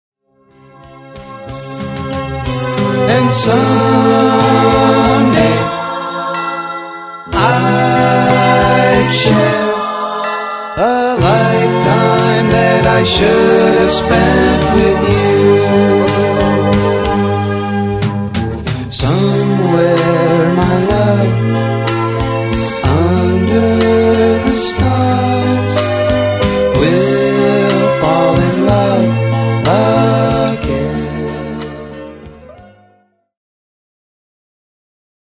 USING AN ANALOG 8-TRACK.
THESE TUNES HAVE A RETRO FLARE TO THEM.
IF YOU'RE A FAN OF 60'S AND 70'S POP ROCK,